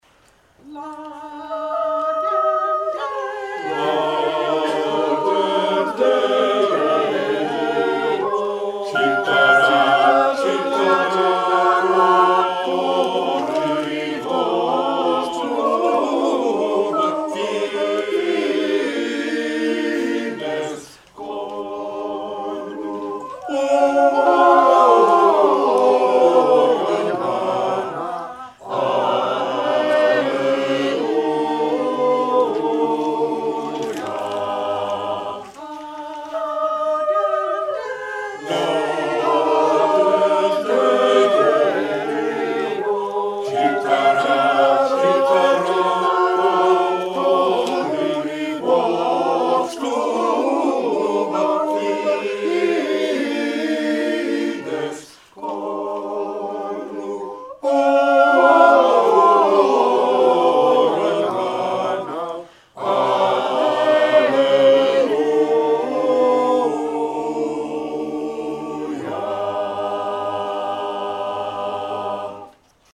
The Renaissance Street Singers' 46th-Anniversary Loft Concert, 2019